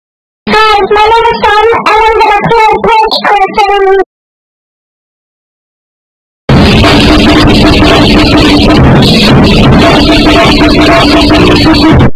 *bead bed shake* Hi My Name This Time Sound Effects Free Download.